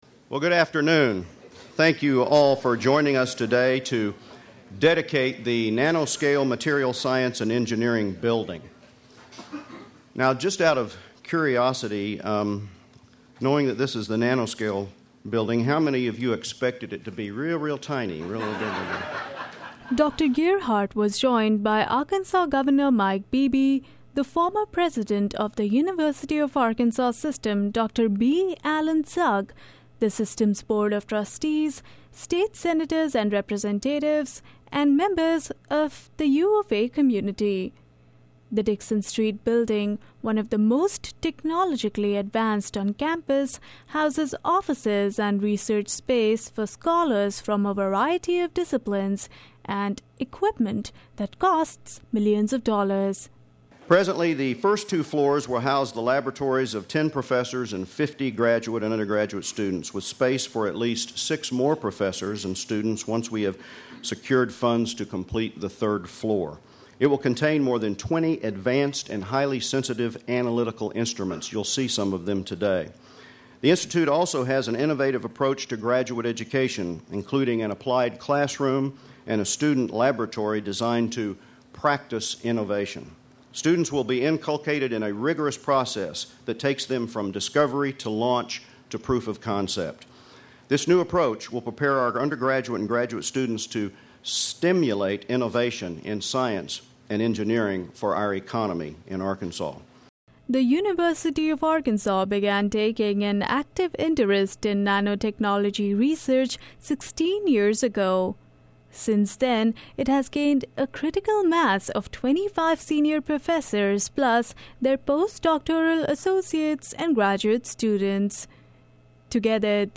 Friday afternoon, University of Arkansas Chancellor Dr. G. David Gearhart and Governor Mike Beebe dedicated the Nanoscale Material Science and Engineering building on Dickson Street on campus.